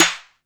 METL PIPE.wav